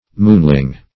Moonling \Moon"ling\, n. A simpleton; a lunatic.